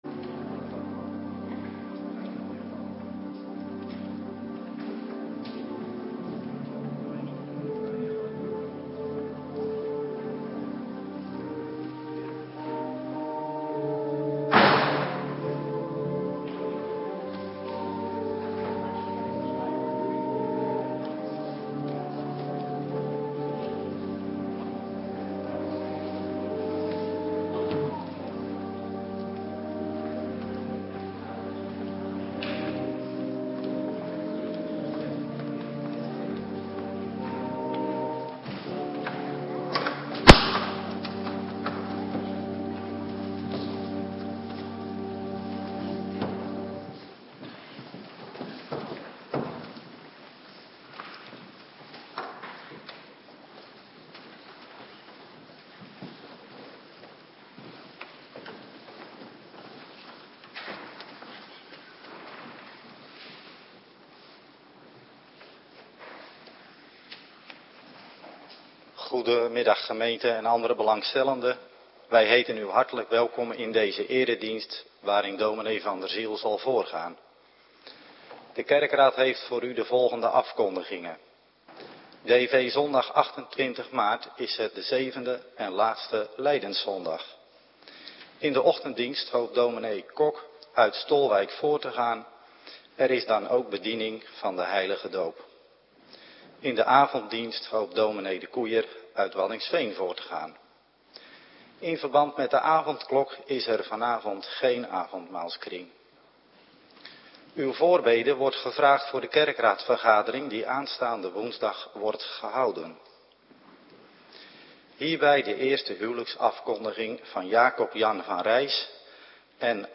Middagdienst Heilig Avondmaal
Locatie: Hervormde Gemeente Waarder